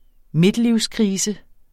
Udtale [ ˈmedliws- ]